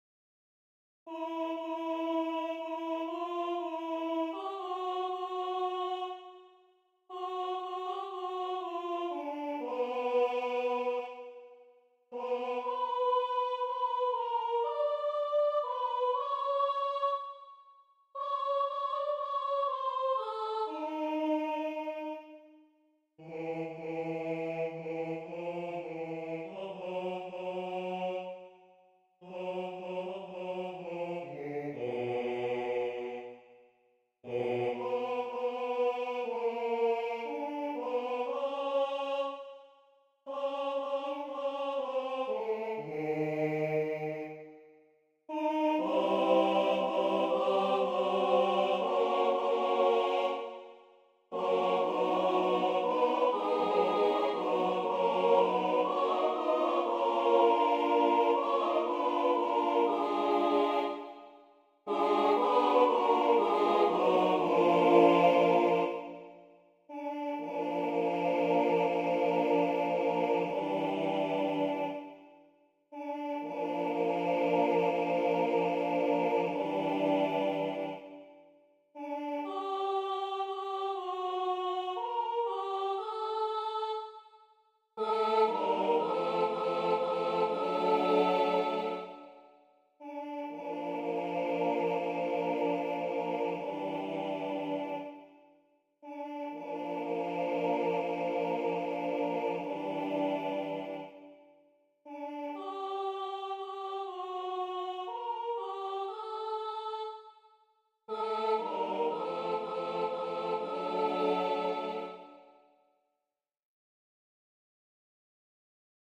Number of voices: 4vv Voicing: SATB Genre: Secular, Lied
Language: German Instruments: A cappella